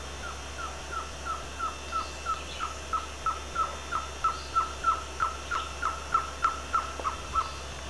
SURUCUÁ-VARIADO
Trogon surrucura (Vieillot, 1817)
Nome em Inglês: Surucua Trogon